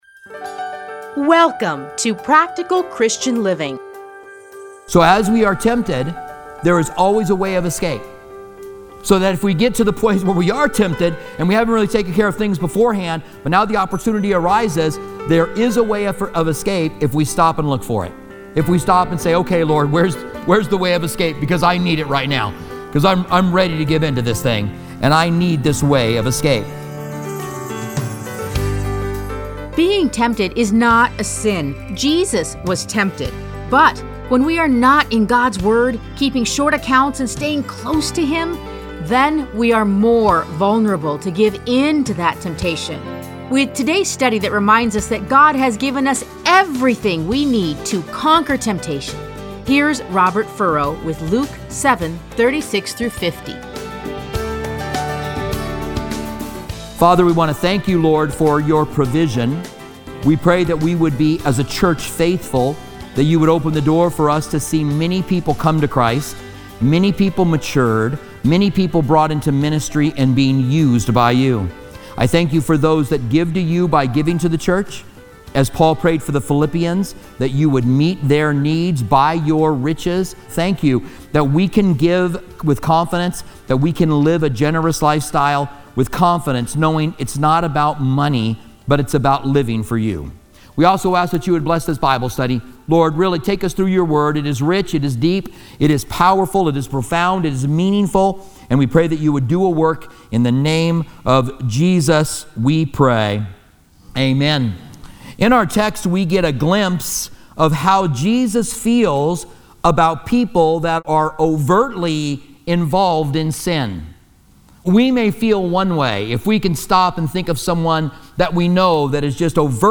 Listen to a teaching from Luke 7:36-50.